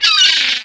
A_GULL2.mp3